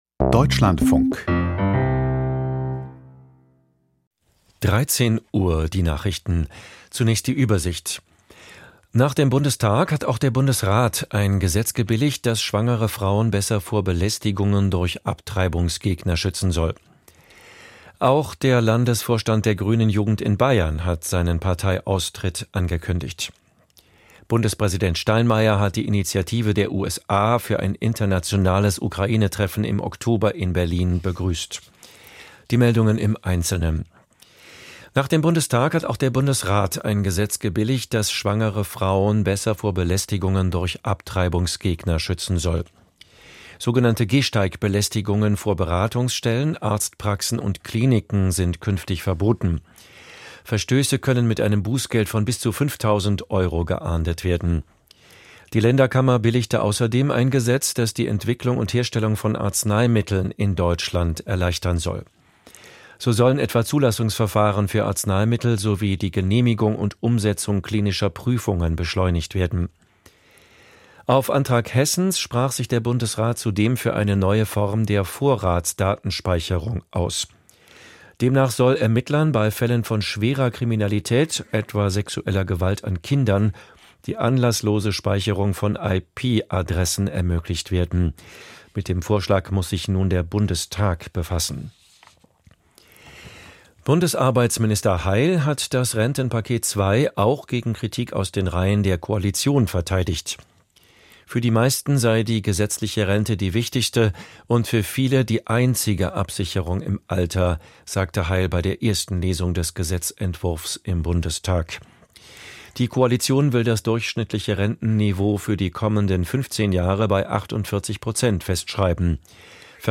Bremsen die USA ihre Unterstützung für die Ukraine? - Interview mit Politologin